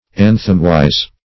anthemwise - definition of anthemwise - synonyms, pronunciation, spelling from Free Dictionary Search Result for " anthemwise" : The Collaborative International Dictionary of English v.0.48: Anthemwise \An"them*wise`\, adv.